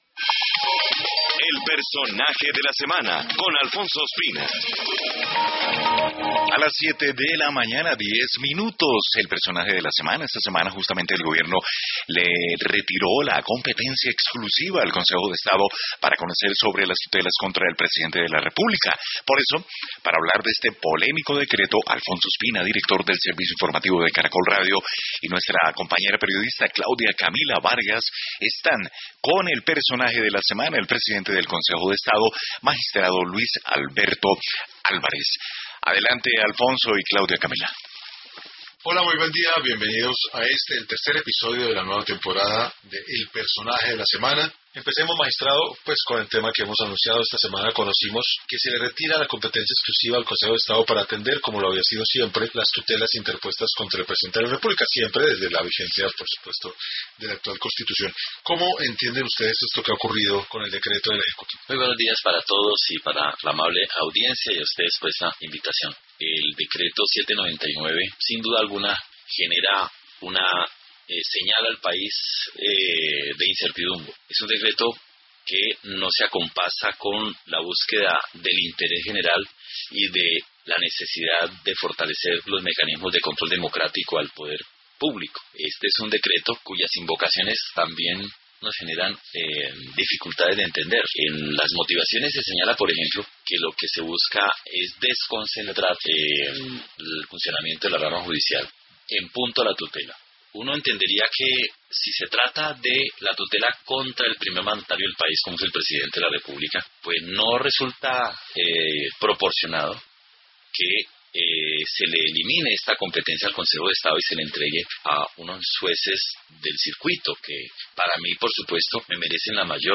En diálogo con Caracol Radio, el magistrado Luis Alberto Álvarez confirmó que estudian 8 demandas contra el decreto que le quitó competencias a la Corporación.